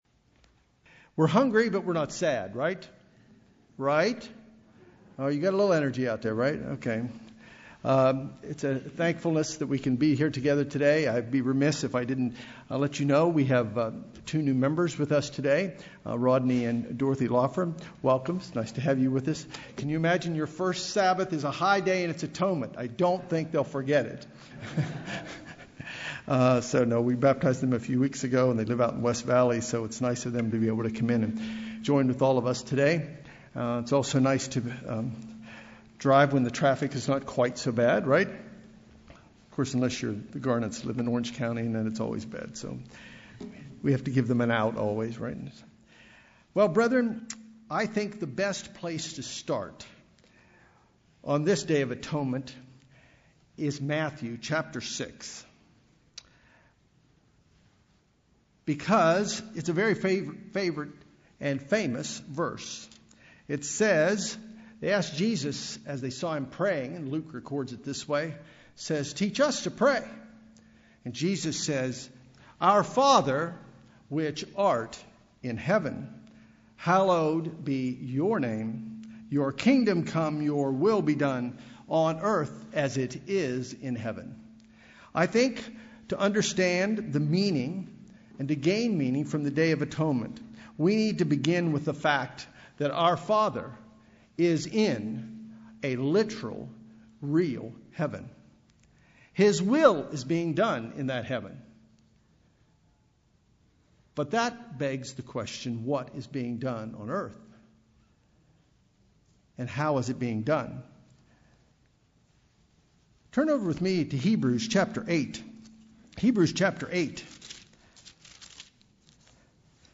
The Day of Atonement pictures a nullification and total removal of sin from earth and the heavenly realm. This message, given on this important day, provides an overview of the physical symbolism involved in the rites of the Day of Atonement, how the typology relates to Jesus Christ and His role and actions in our lives today.